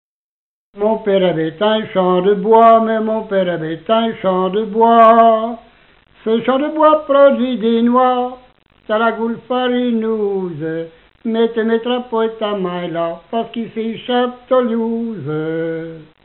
Note chantée dans les veillées
Pièce musicale inédite